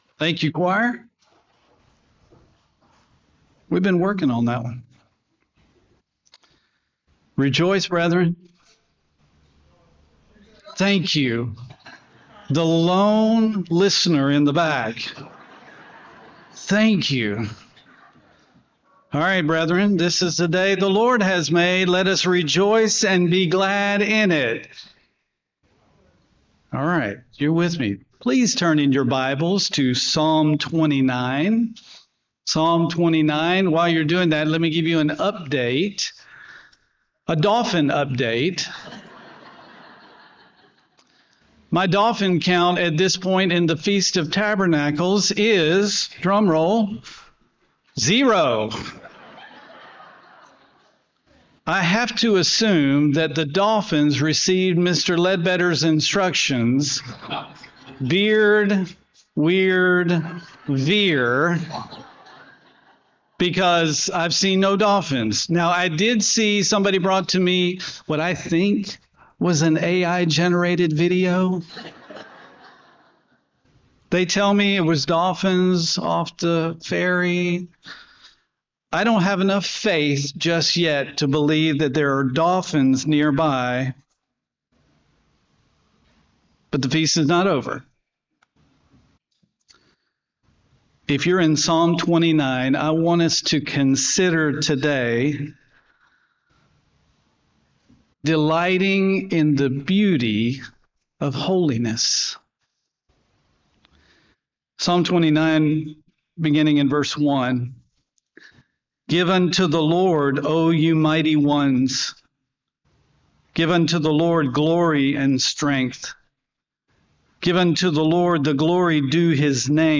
Sermons
Given in Aransas Pass, Texas